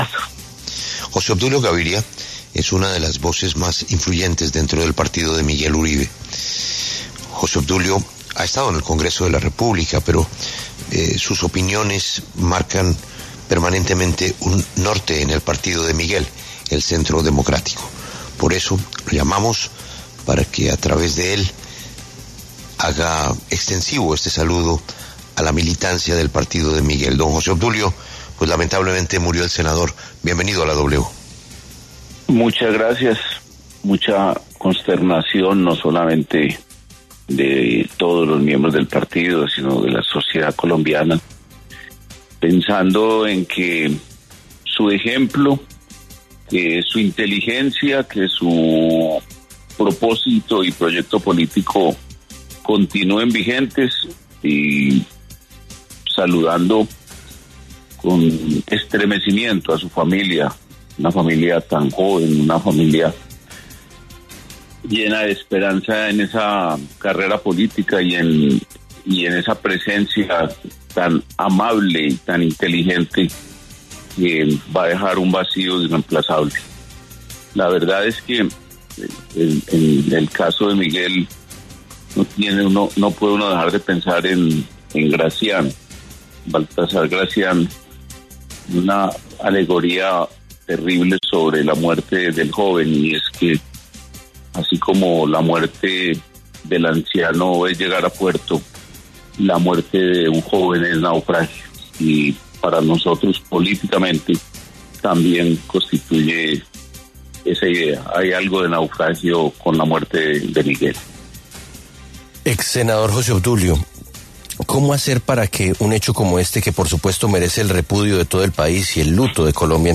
José Obdulio Gaviria, exsenador de Colombia, habló en los micrófonos de La W sobre la partida de Miguel Uribe tras haber sido víctima de un atentado en medio de un acto de campaña en Bogotá, el pasado 7 de junio.